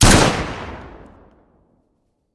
shot2.mp3